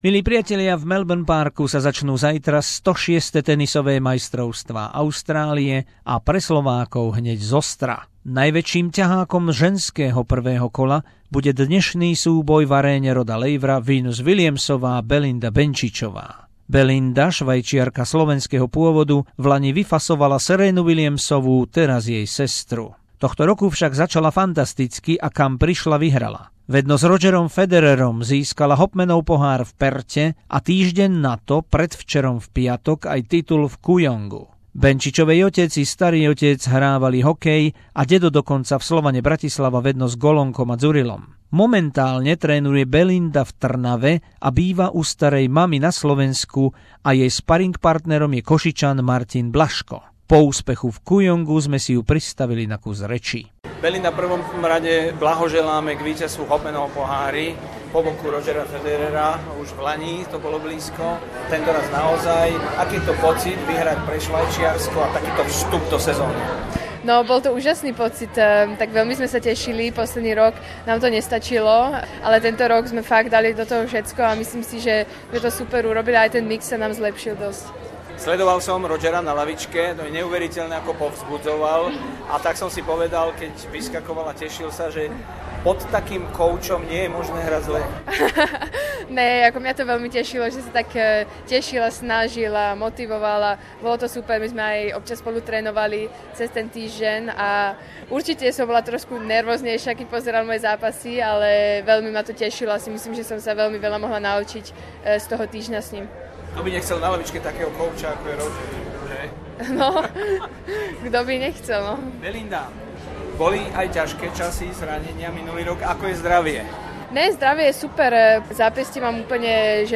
Rozhovor so švajčiarskou tenistkou Belindou Benčičovou slovenského pôvodu na prahu Australian Open 2018